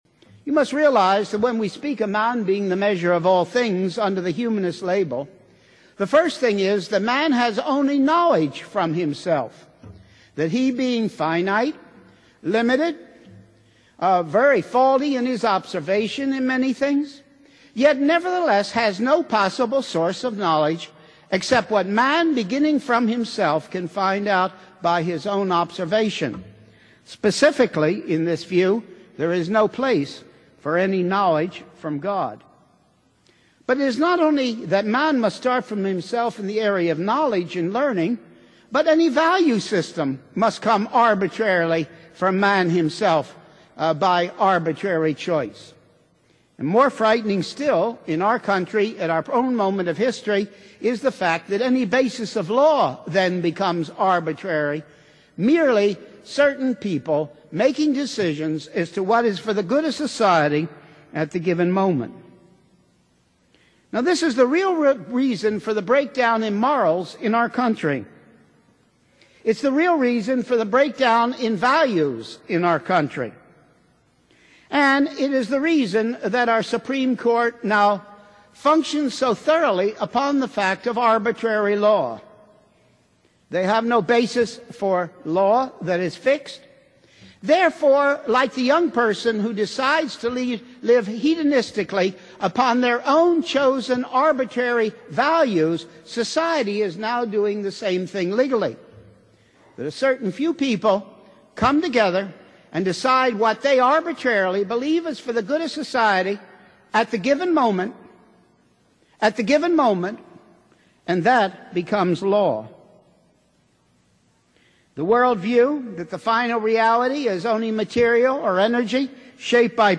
Just a couple of years before he died, Dr. Schaeffer spoke at the Coral Ridge Presbyterian Church in Fort Lauderdale, Florida. He presented the heart of the content of his bestselling book A Christian Manifesto.